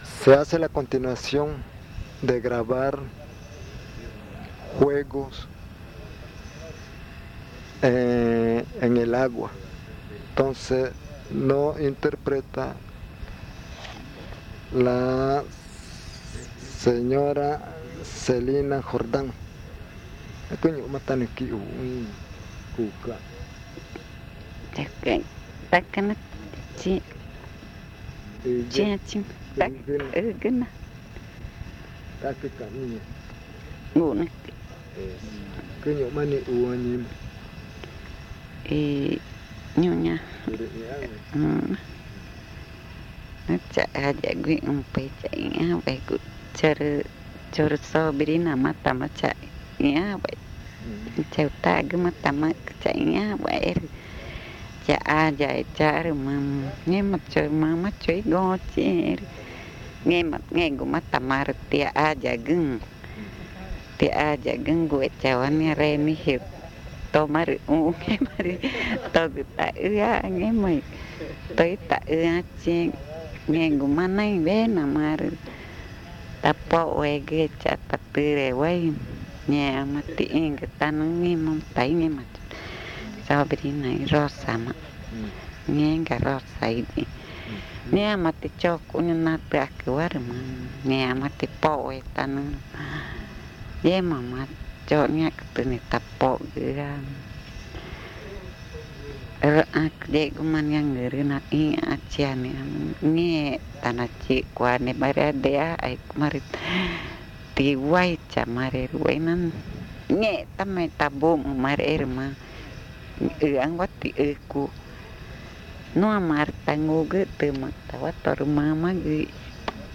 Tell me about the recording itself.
San Antonio de los Lagos, Amazonas (Colombia)